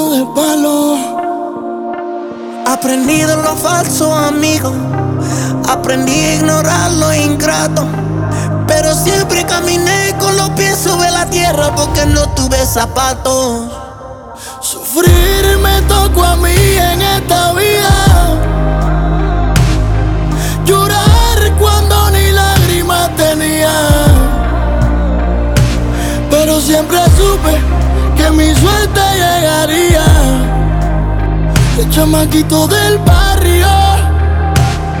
Жанр: Латиноамериканская музыка